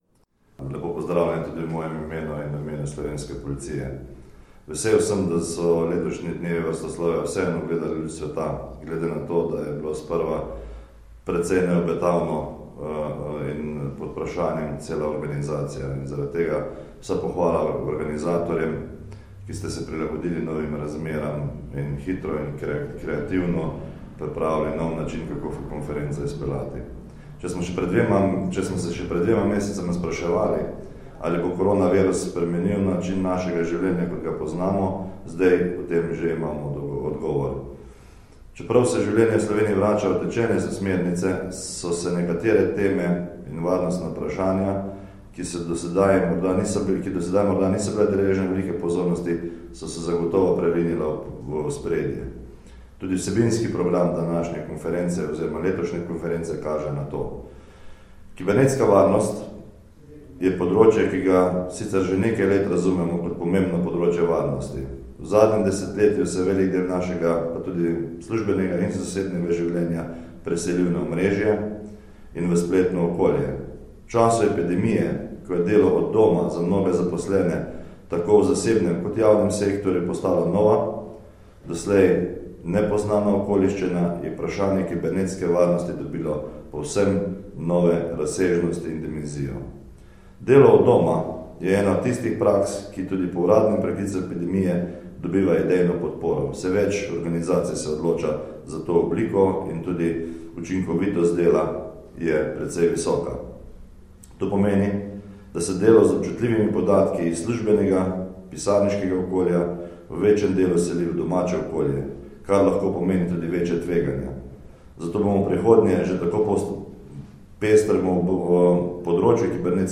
V. d. generalnega direktorja policije mag. Anton Travner je danes, 3. junija 2020, nagovoril udeležence letošnje konference dnevov varstvoslovja.
Zvočni posnetek nagovora v. d. generalnega direktorja policije mag. Antona Travnerja